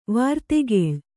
♪ vārtegēḷ